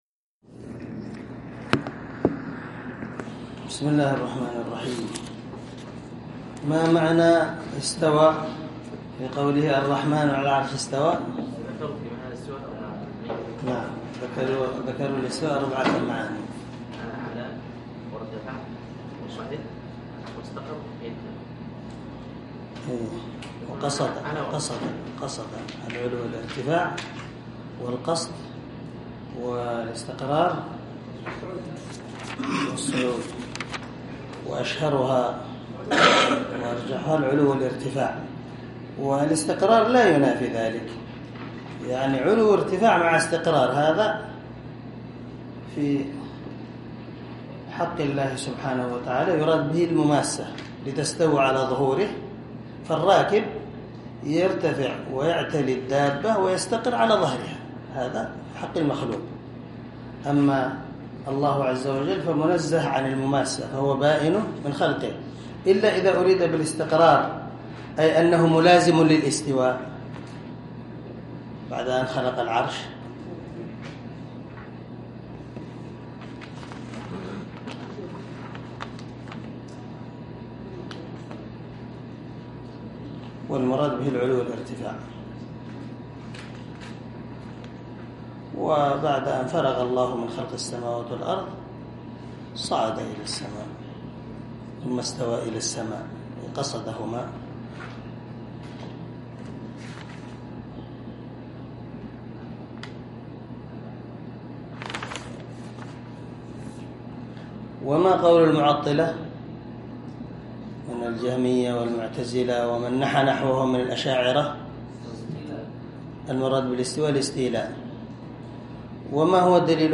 عنوان الدرس: الدرس الثامن الثلاثون
دار الحديث- المَحاوِلة- الصبيحة.